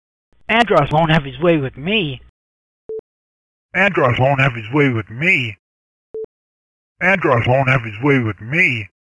You’ve not given us a lot to work with : it sounds like it was recorded on a narrow bandwidth device like a mobile phone, or digital voice recorder on a low sample rate (~8KHz).
I’ve aged you about 25 years but it still sounds like you’re on the phone …